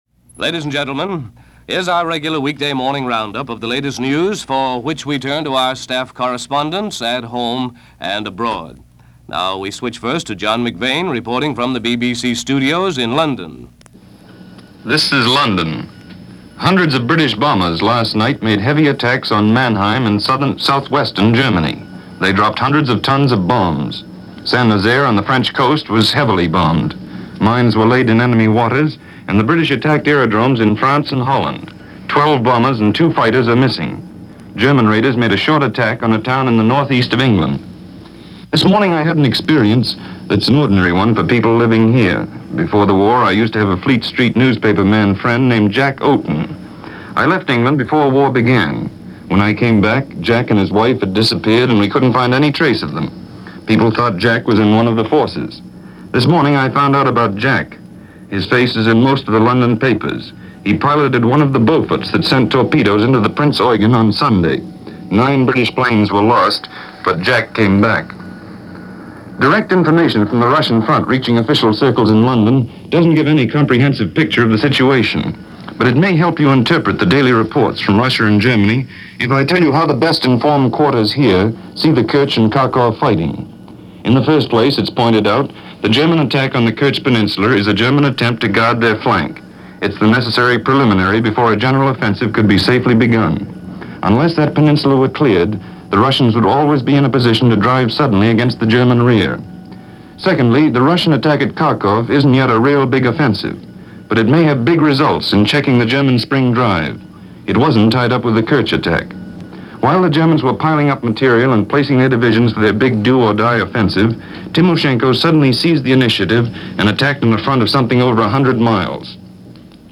May 20, 1942 - Marshal Timoshenko And The Eastern Front - One Of Those Things That just might be pulled off - News from The Blue Network.